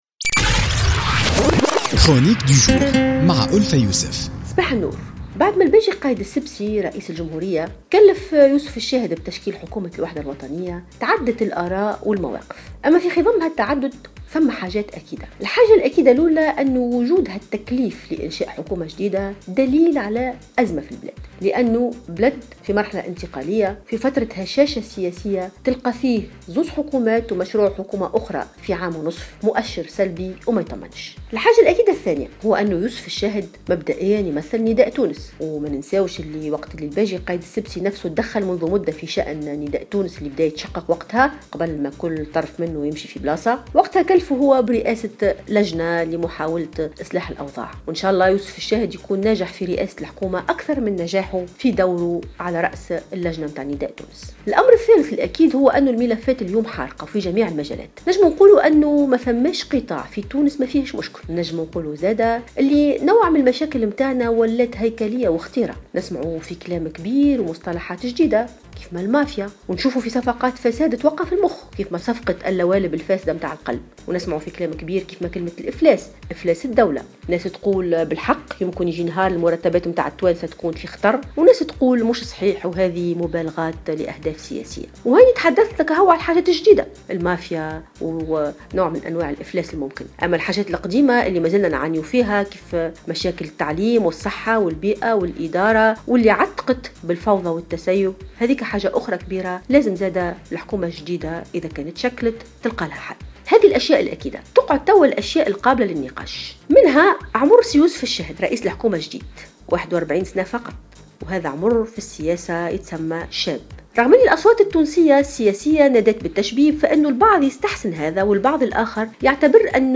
تطرقت الكاتبة ألفة يوسف في افتتاحية اليوم الجمعة 5 أوت 2016 إلى ردود الفعل التي تلت اختيار يوسف الشاهد لرئاسة الحكومة .